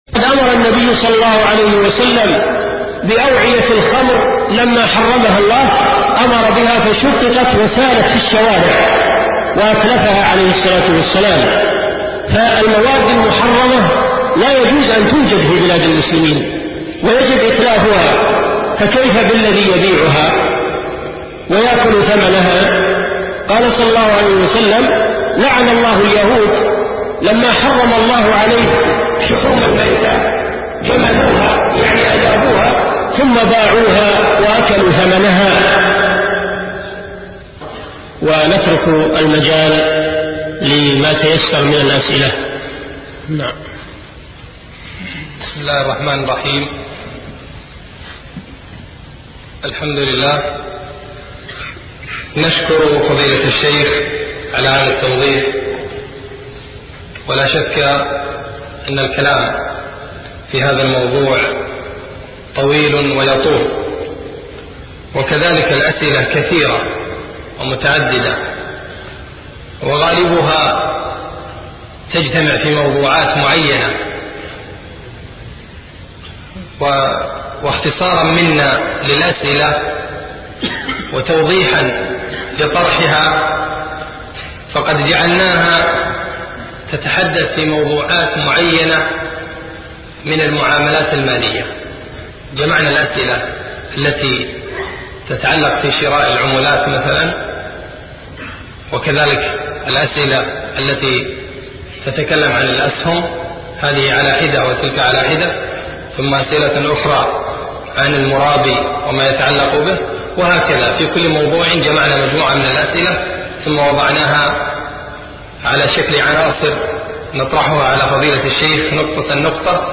شبكة المعرفة الإسلامية | الدروس | الربا وخطره |صالح بن فوزان الفوزان